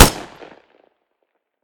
smg-shot-07.ogg